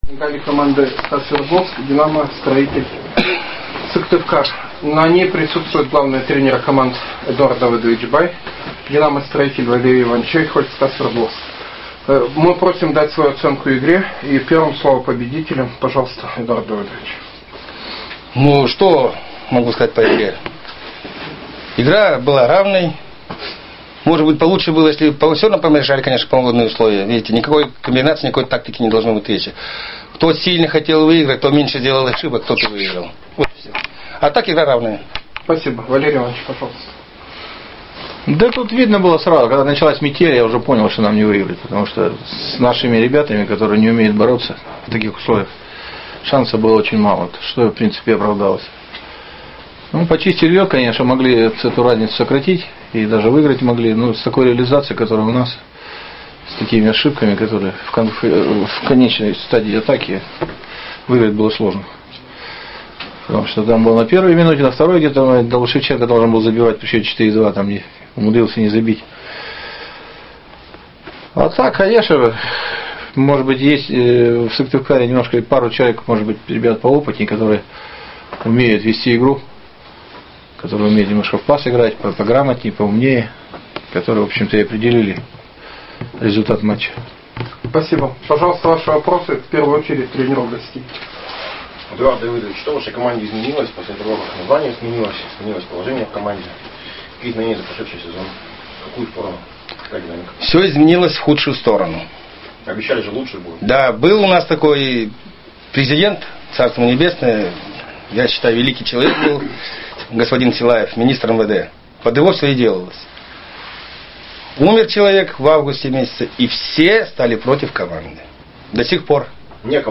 ПРЕСС-КОНФЕРЕНЦИЯ